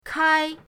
kai1.mp3